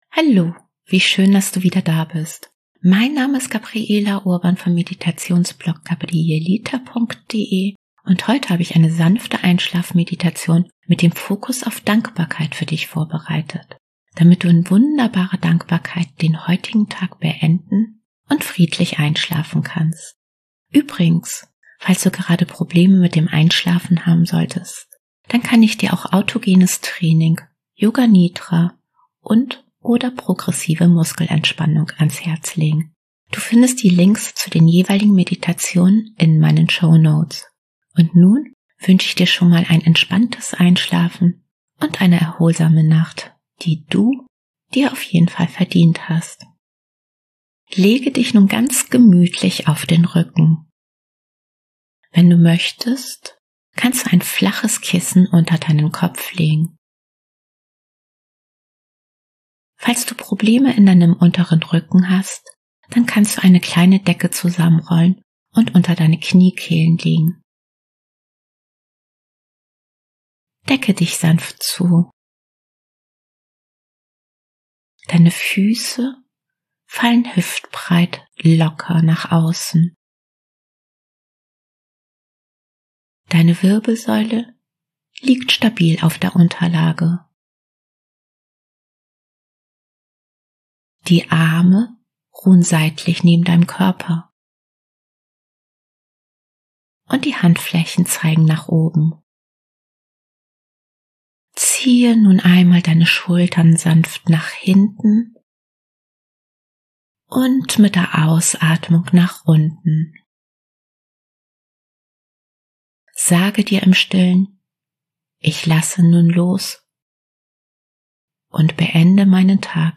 Dann habe ich heute eine wohltuende Einschlafmeditation für dich.